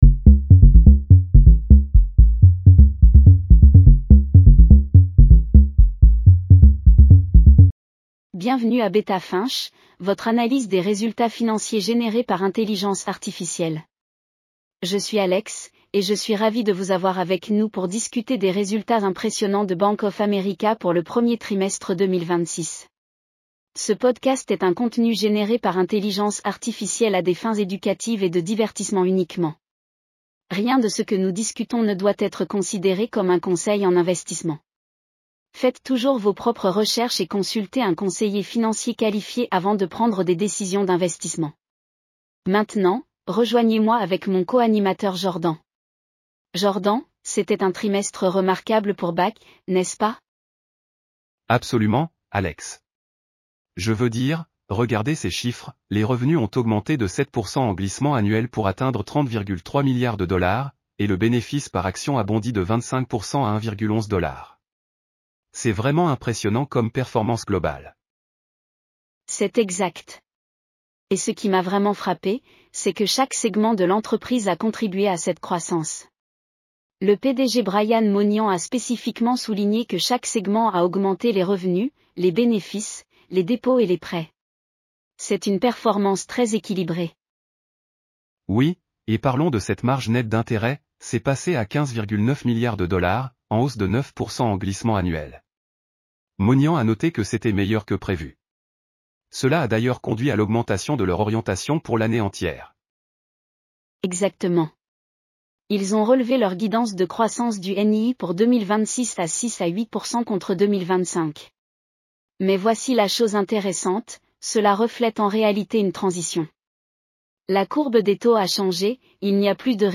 Bank of America Q1 2026 earnings call breakdown.